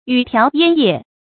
雨條煙葉 注音： ㄧㄩˇ ㄊㄧㄠˊ ㄧㄢ ㄧㄜˋ 讀音讀法： 意思解釋： ①雨中的柳條，煙霧中的柳葉。